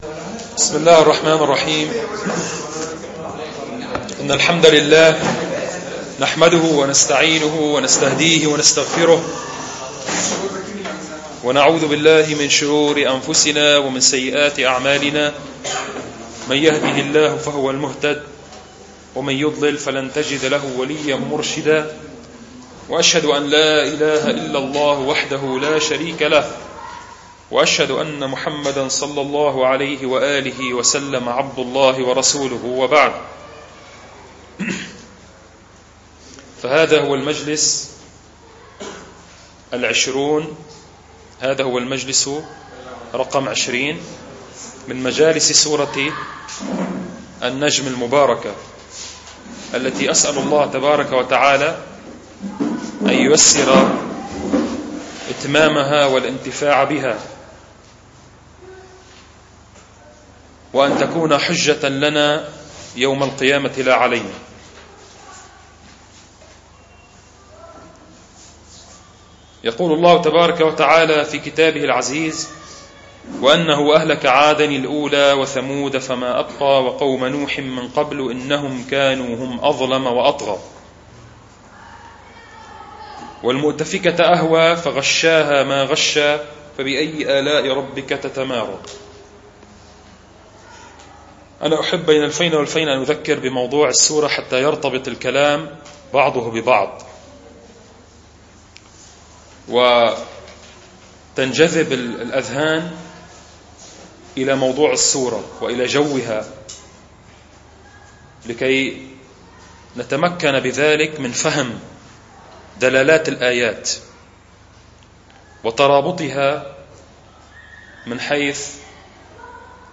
المحاضر
المكان : ثانوية المنار الإسلامية